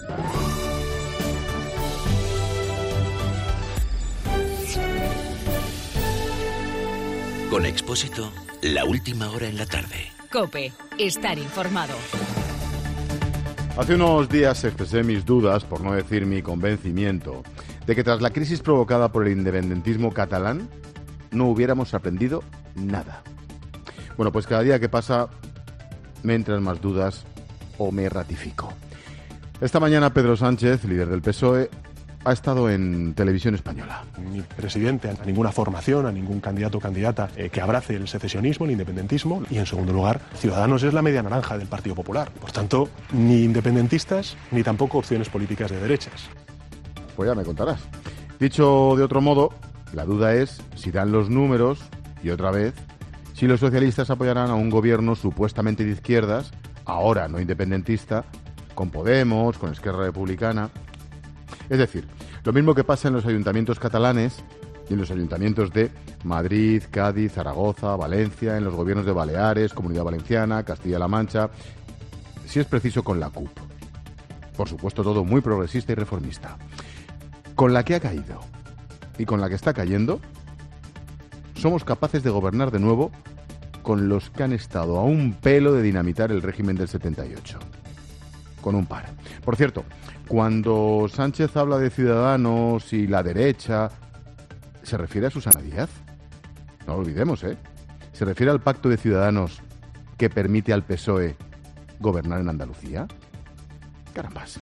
Monólogo de Expósito
El comentario de Ángel Expósito tras escuchar la entrevista a Pedro Sánchez en televisión.